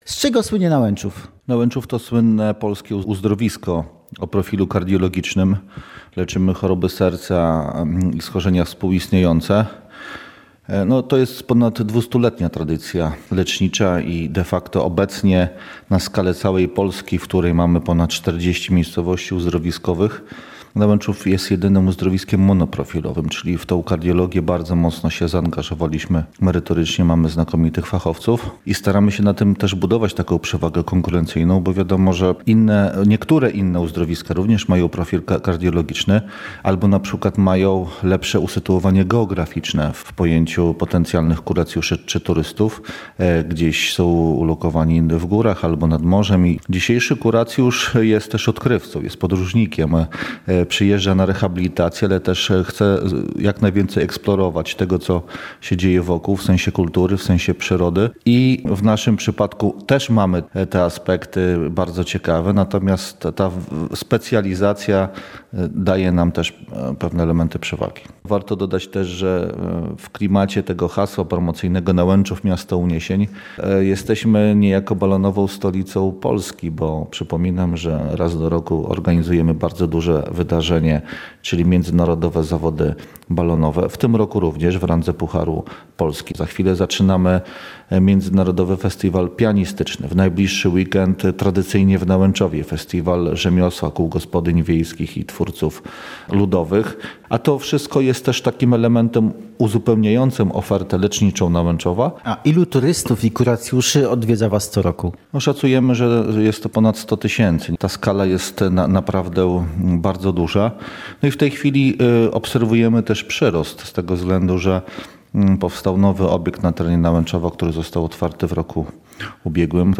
Czy lubelskie miasteczko zachowa status uzdrowiska? Rozmowa z burmistrzem Nałęczowa